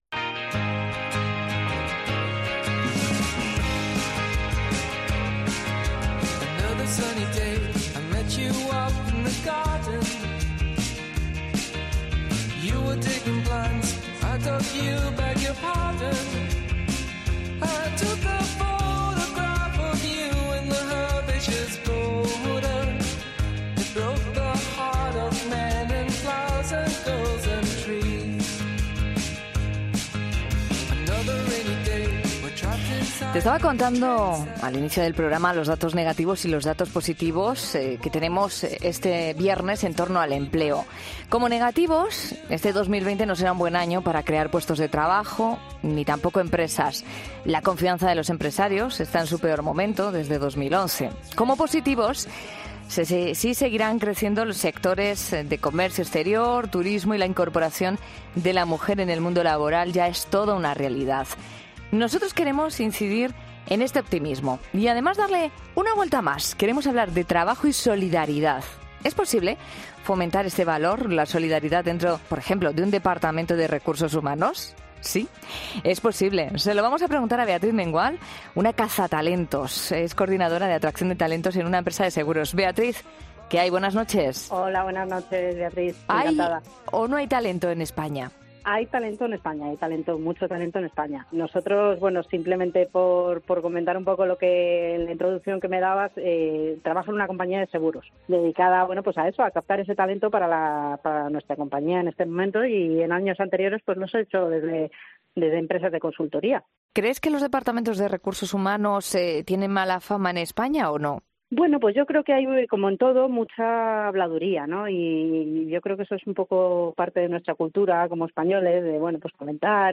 ESCUCHA ESTAS ENTREVISTAS EN 'LA NOCHE' CLICANDO AQUÍ Los tres han estado en 'La Noche' en mitad de un clima algo confuso en la realidad laboral de España. 2020 no será un buen año para crear puestos de trabajo ni empresas.